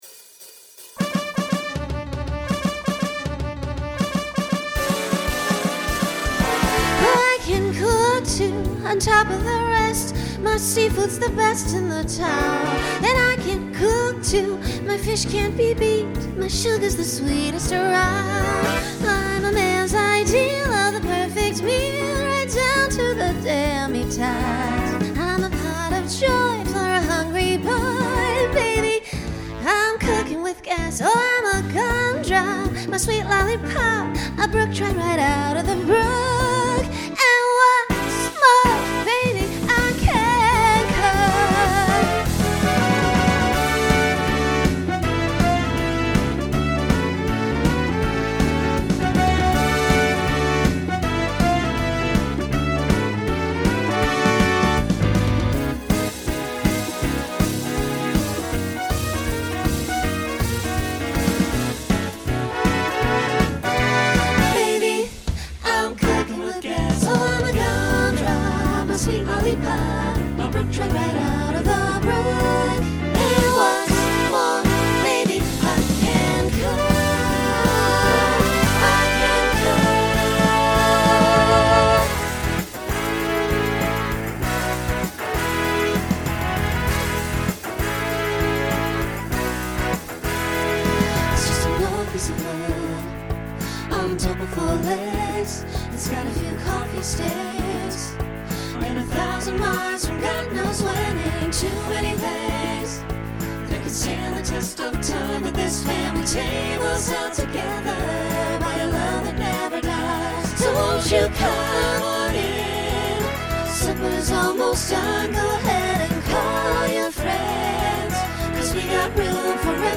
Broadway/Film , Rock Instrumental combo
Solo Feature Voicing SATB